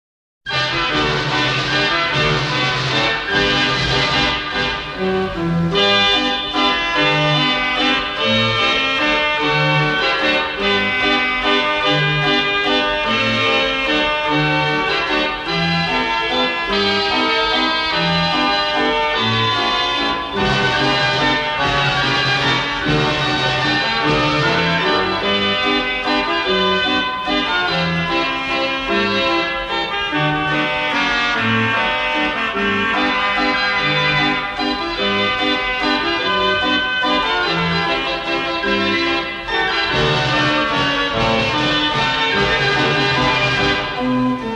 Gavioli/deKliest Fair Organ